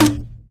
etfx_shoot_grenade.wav